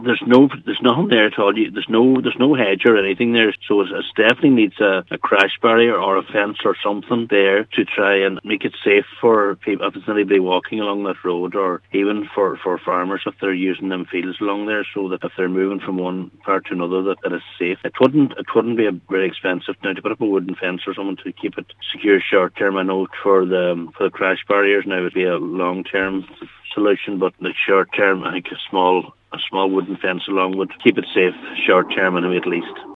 Cllr Harley says, at the very least, a fence is needed: